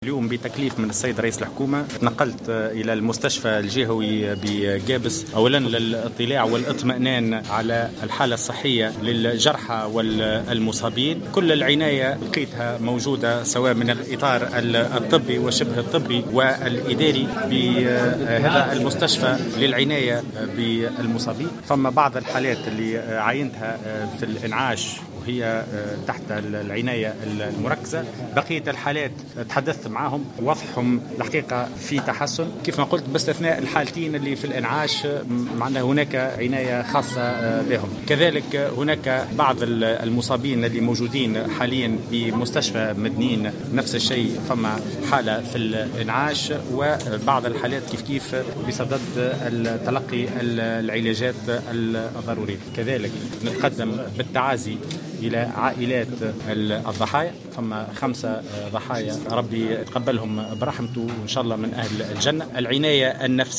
قال وزير الداخلية، هشام الفوراتي، في تصريح لمراسل الجوهرة أف أم، إن جرحى حادث المرور الذي جد صباح اليوم الأحد في قابس، يحظون بالعناية الضرورية من الإطار الطبي وشبه الطبي بمستشفى قابس.